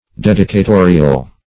Dedicatorial \Ded`i*ca*to"ri*al\, a.